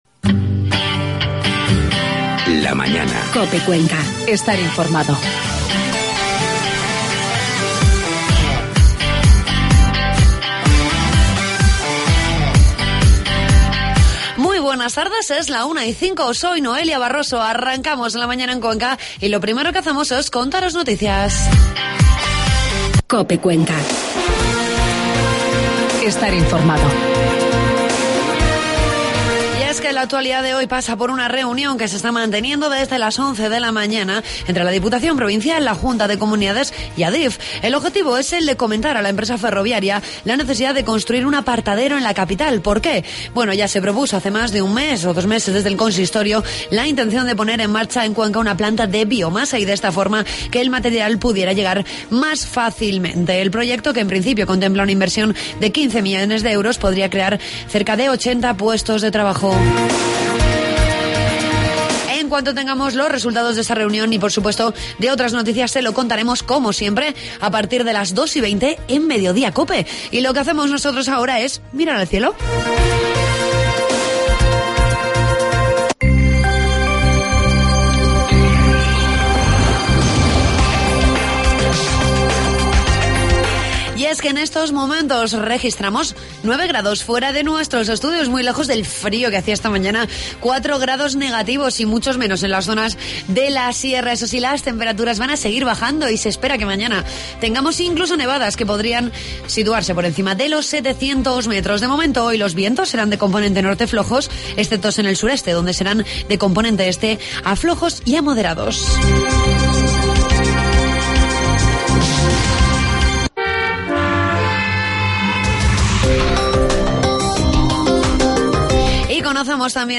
Entrevistamos a la portavoz socialista en la Diputación, Carmen Torralaba, con la que hablamos de diferentes asuntos referentes a la Insitución Provincial.